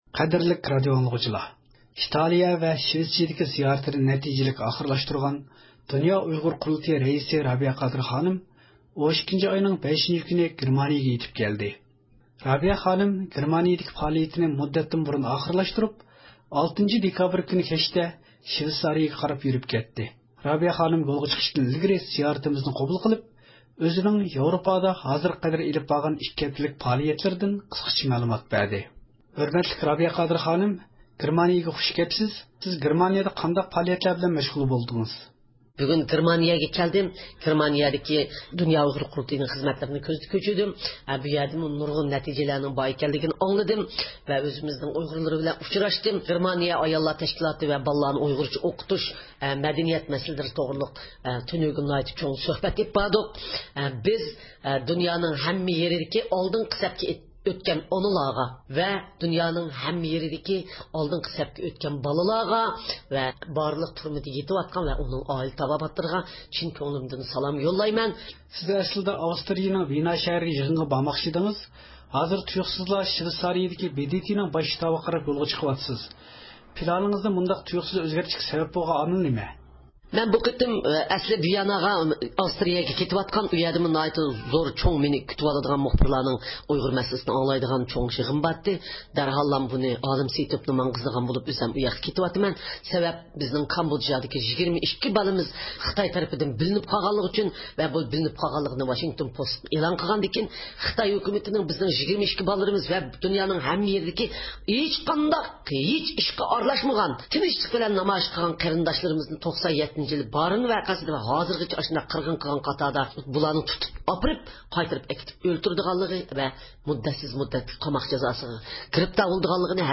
رابىيە قادىر خانىم يولغا چىقىشتىن ئىلگىرى زىيارىتىمىزنى قوبۇل قىلىپ، ئۆزىنىڭ ياۋرۇپادا ھازىرغا قەدەر ئېلىپ بارغان ئىككى ھەپتىلىك پائالىيەتلىرىدىن قىسقىچە مەلۇمات بەردى.